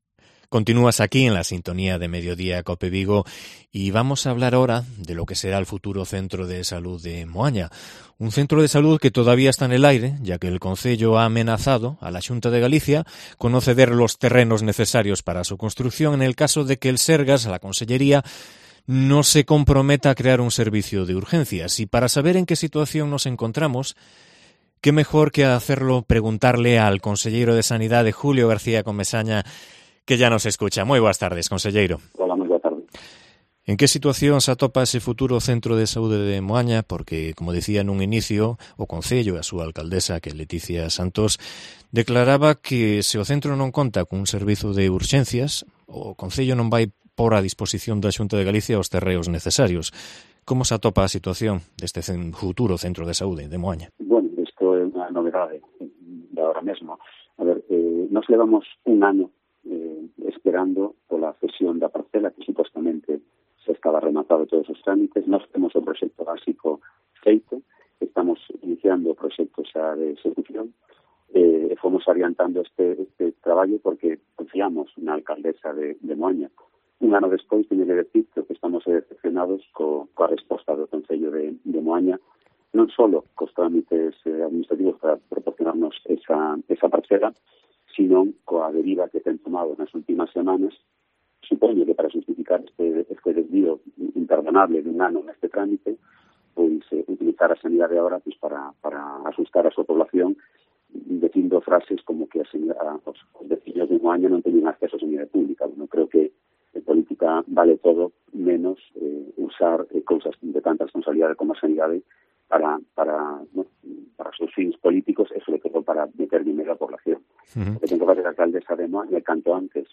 Entrevista con Julio García Comesaña, conselleiro de Sanidade